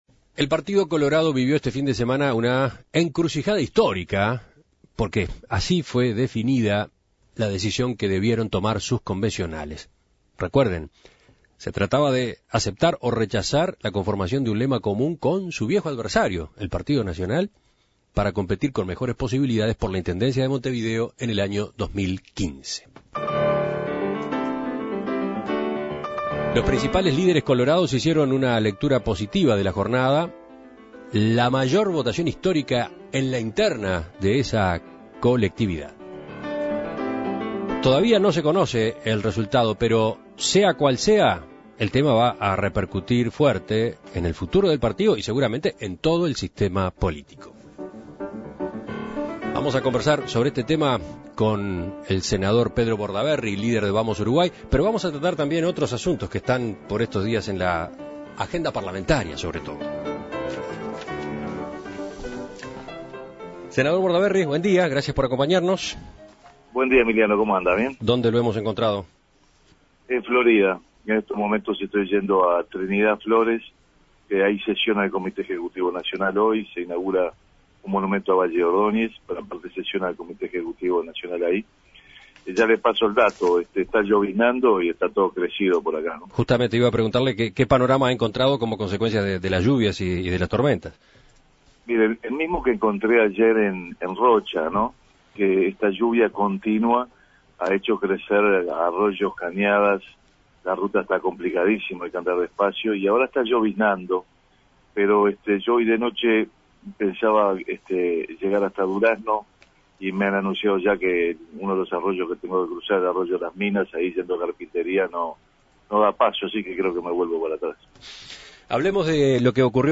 Un 93% de los convencionales del Partido Colorado (PC) participaron el sábado de la votación sobre el acuerdo con el Partido Nacional para la elección departamental de Montevideo de 2015. En diálogo con En Perspectiva, el senador y líder de Vamos Uruguay Pedro Bordaberry destacó la "altísima participación" y señaló que con jornadas de este tipo se profundiza la horizontalidad que, según dijo, siempre ha caracterizado al PC.
Entrevistas